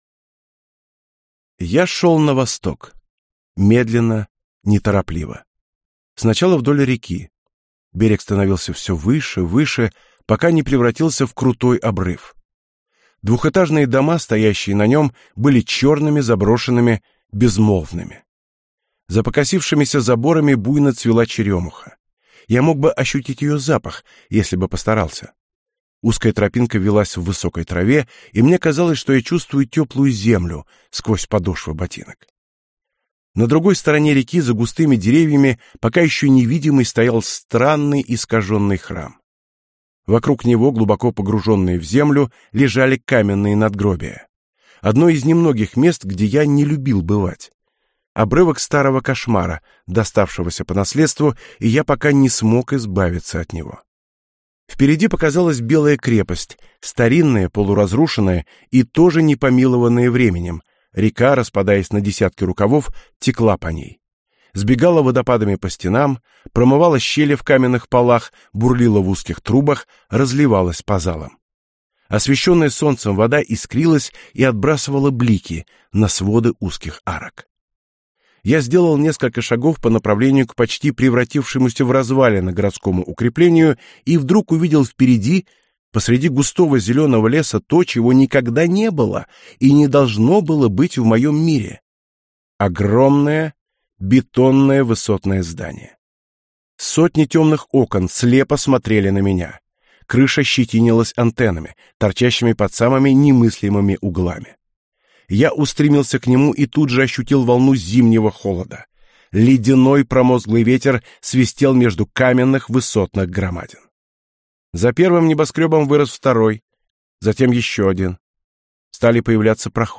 Аудиокнига Мастер снов - купить, скачать и слушать онлайн | КнигоПоиск
Аудиокнига «Мастер снов» в интернет-магазине КнигоПоиск ✅ Фэнтези в аудиоформате ✅ Скачать Мастер снов в mp3 или слушать онлайн